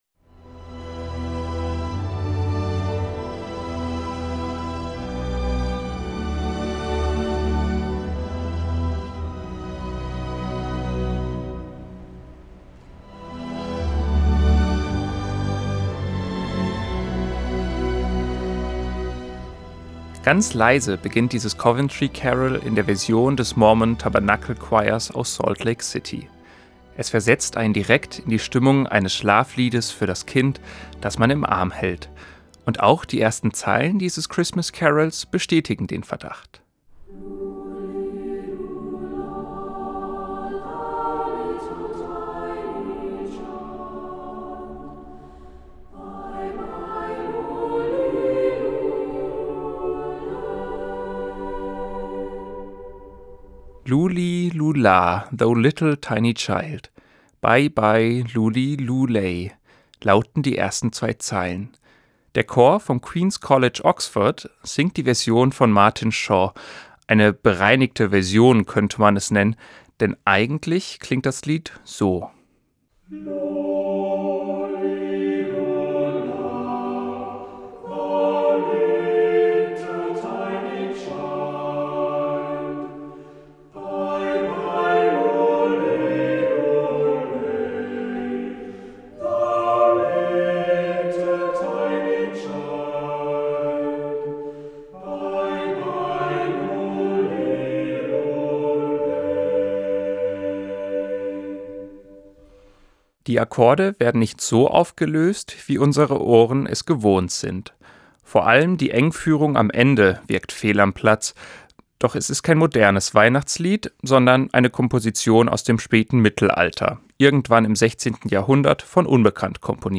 Ganz leise beginnt dieses Coventry Carol in der Version des Mormon Tabernacle Choirs aus Salt Lake City. Es versetzt einen direkt in die Stimmung eines Schlafliedes für das Kind, das man im Arm hält.
Die Akkorde werden nicht so aufgelöst, wie unsere Ohren es gewohnt sind. Vor allem die Engführung am Ende wirkt fehl am Platz.